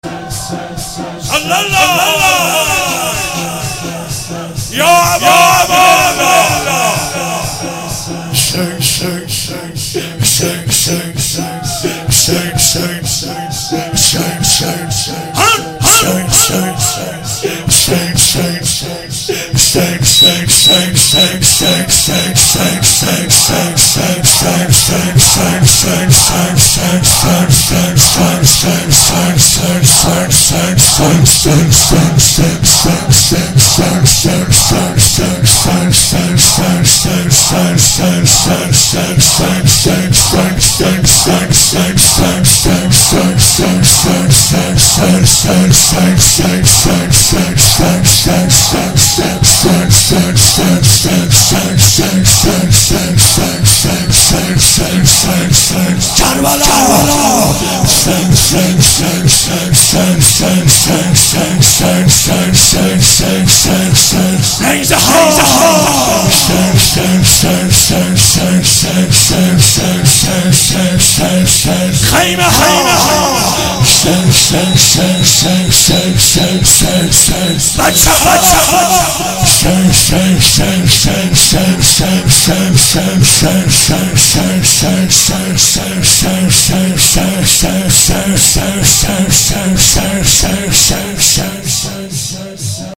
شب سوم رمضان 95، حاح محمدرضا طاهری
واحد، زمینه